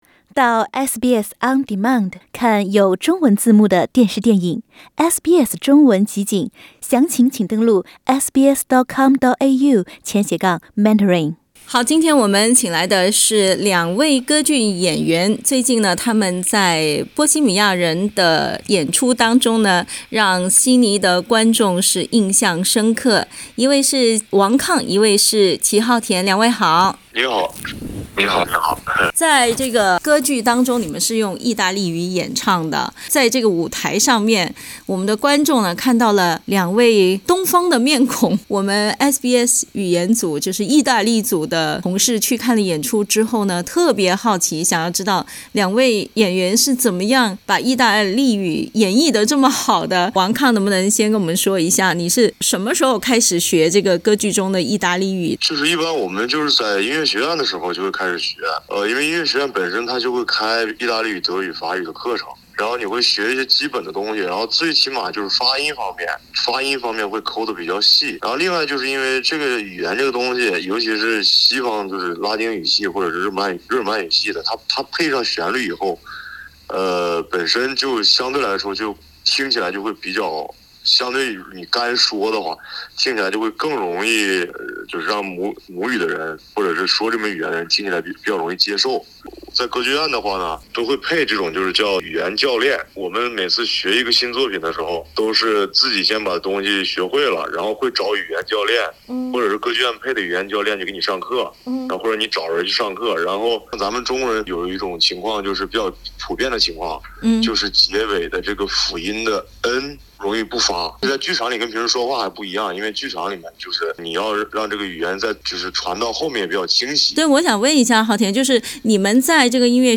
近日，意大利歌剧《波西米亚人》在悉尼歌剧院成功上演，其中两个东方面孔用意大利语演绎了主要的角色，令观众印象深刻。（点击图片收听采访）